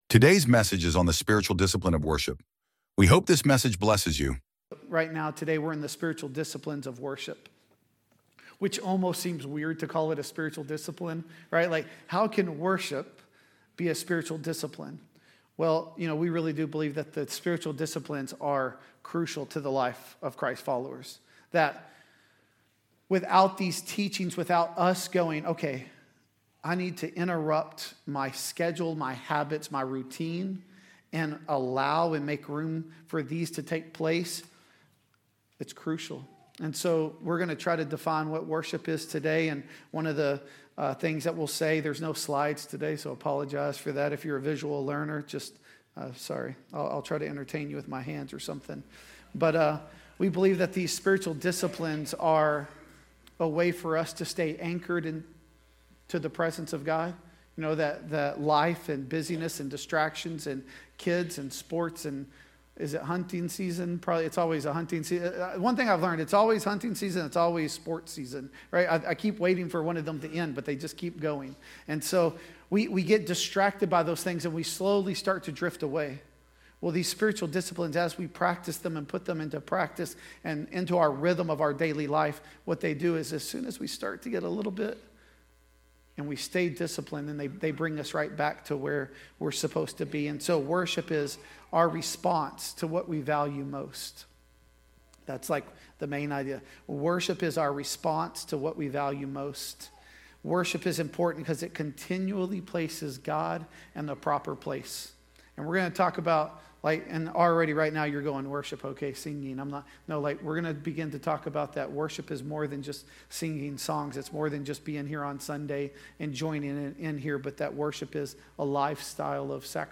The Gathering at Adell Audio Sermons